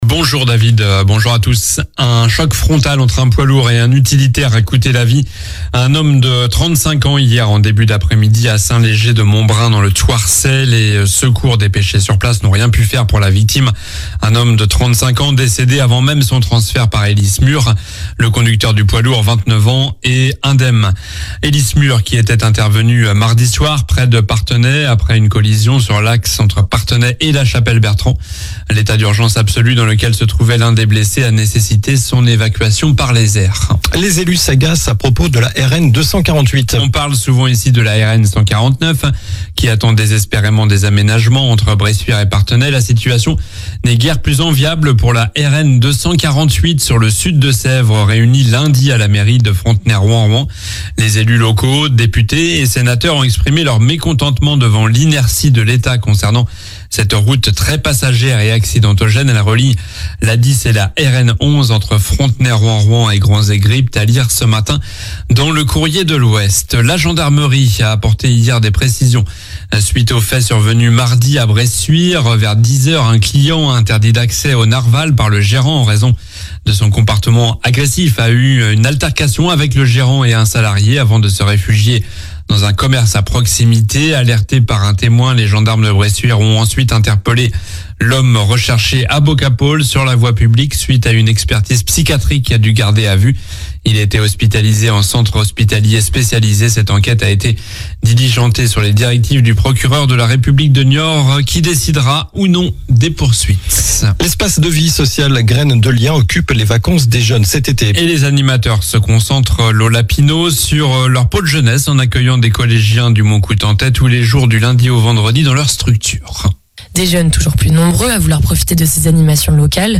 Journal du jeudi 25 juillet (matin)